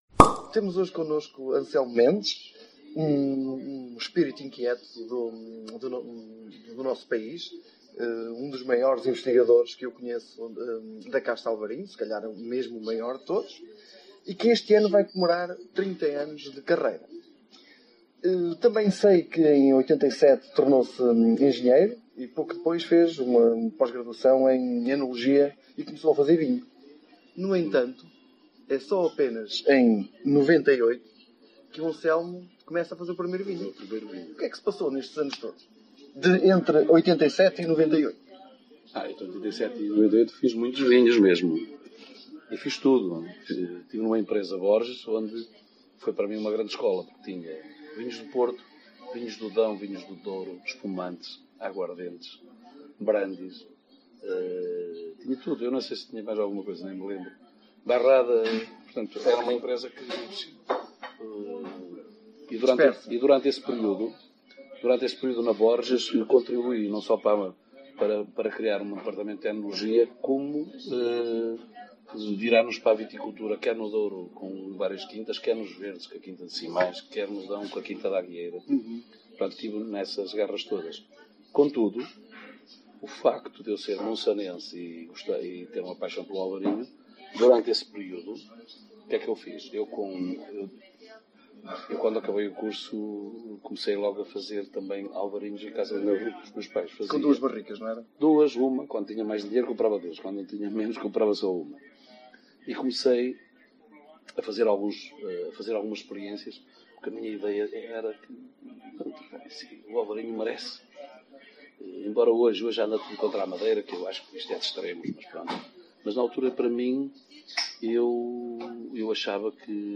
O enólogo também abordou o futuro da região do Vinho Verde. Aviso: Esta entrevista decorreu num café e como tal tem algum ruído de fundo.